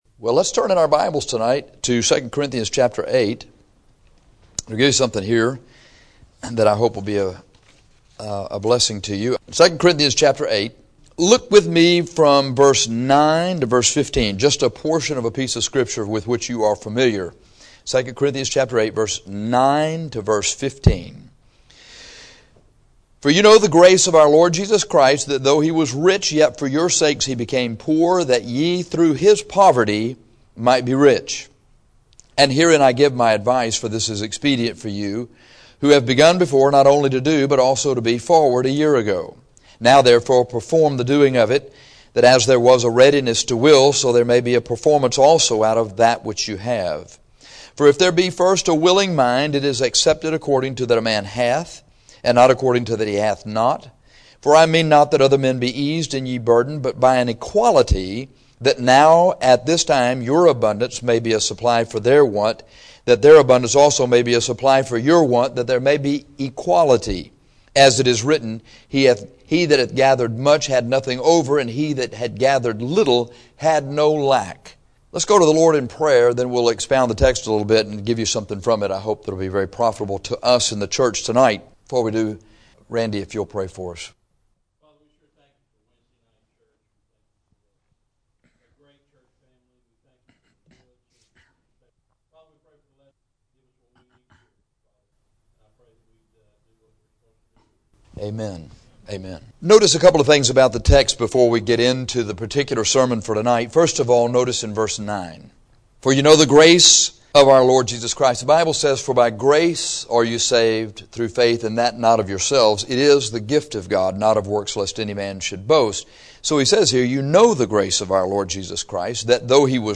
This sermon on churches giving to special needs is an exhortation to churches to give as the Lord directs us to the special needs of missionaries.